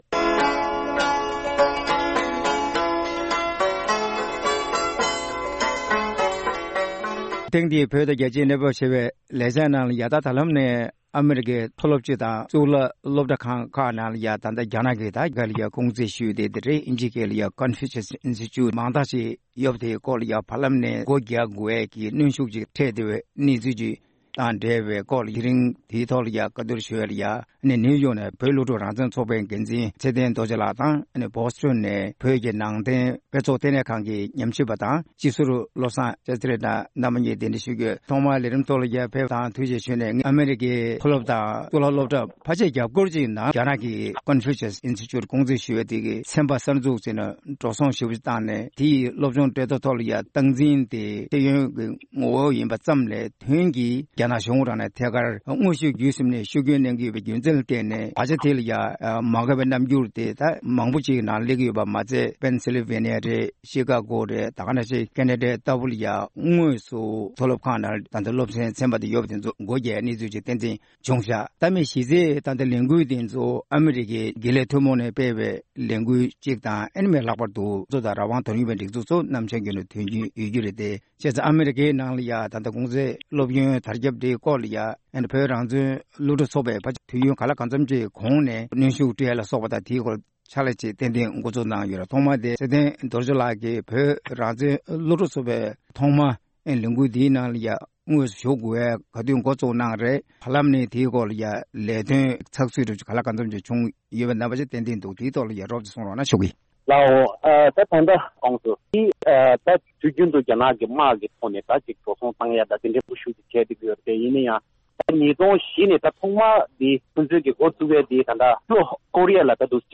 གླེང་མོལ་ཞུས་པ་ཞིག་ལ་གསན་རོགས༎